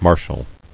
[mar·shal]